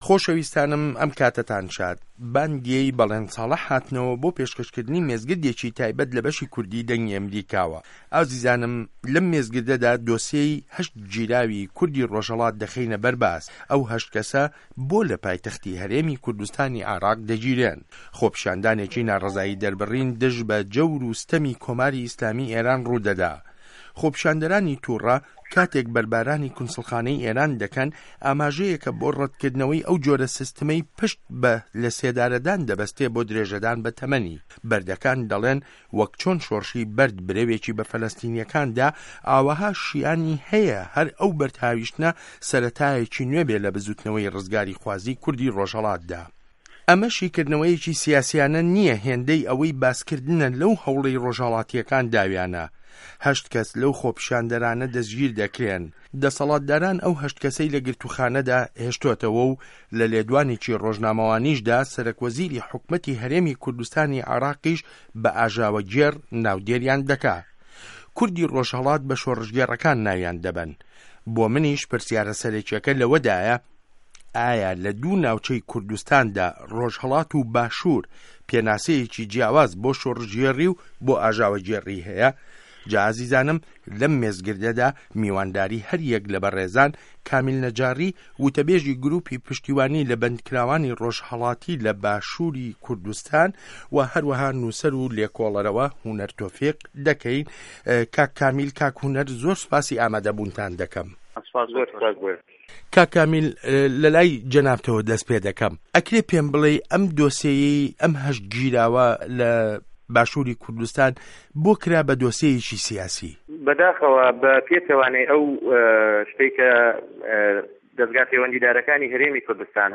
مێزگرد : گیراوه‌ ڕۆژهه‌ڵاتیه‌کان له‌ نێوان شۆڕشگێڕ و ئاژاوه‌گێڕدا